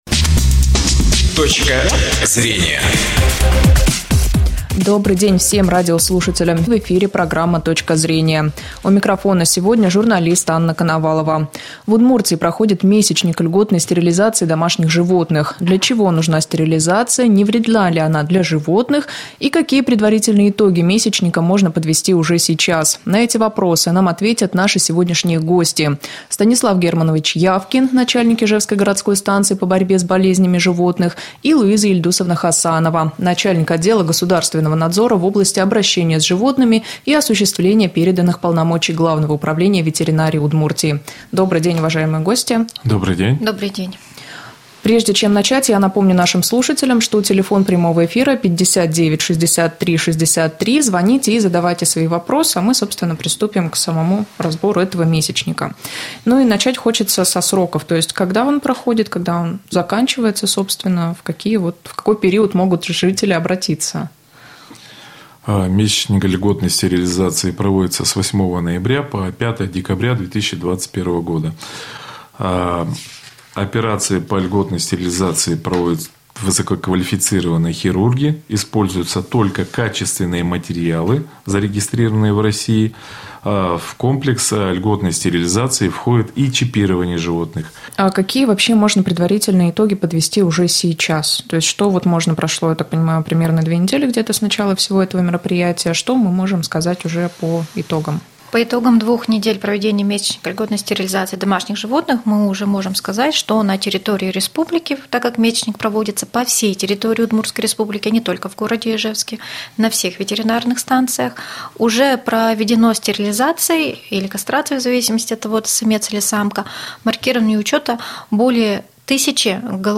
Радиоэфир, программа "Точка зрения", 23.11.2021г.